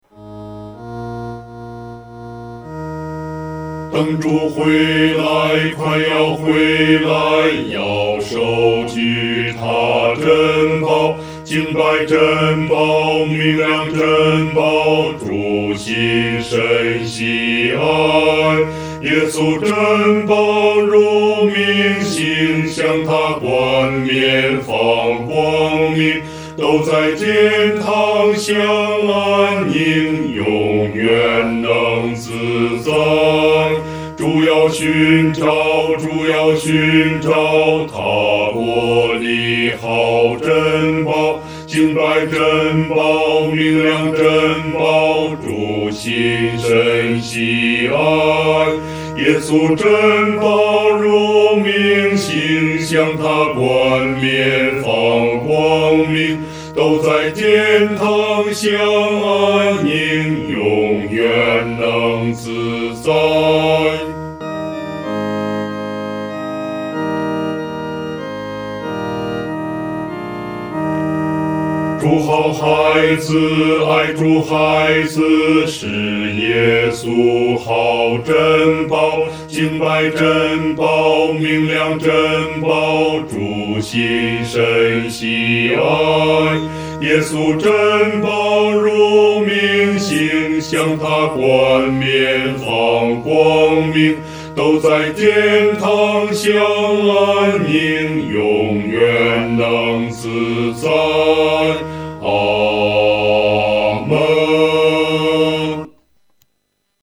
合唱
本首圣诗由网上圣诗班(环球)录制
这是一首充满盼望的诗歌，我们可以用轻快速度弹唱。